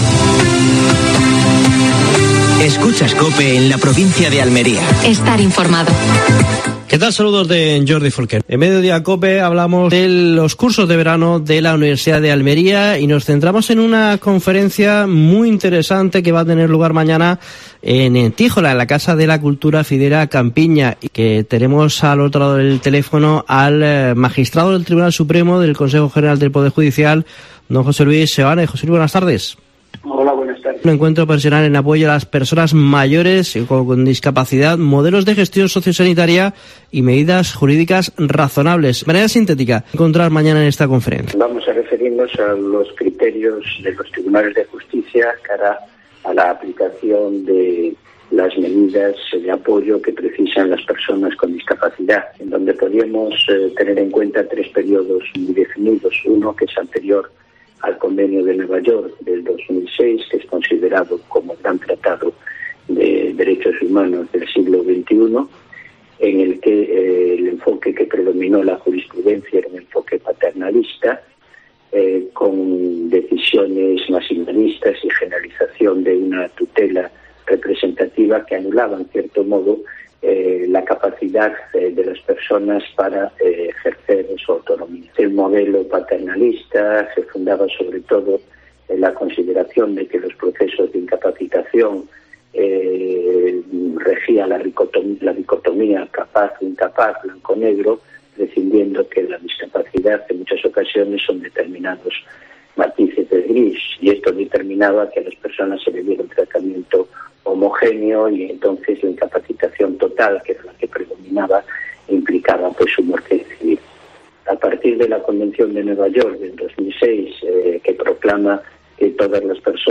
AUDIO: Última hora. Entrevista a José Luis Seoane (magistrado del Tribunal Supremo y del Consejo General del Poder Judicial).